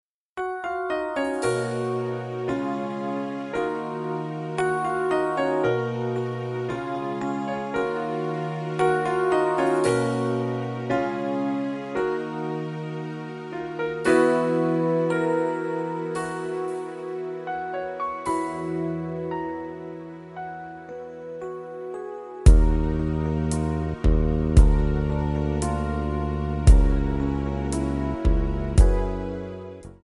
Backing track files: Musical/Film/TV (484)